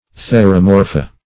Theromorpha - definition of Theromorpha - synonyms, pronunciation, spelling from Free Dictionary
Theromorpha \The`ro*mor"pha\, n. pl.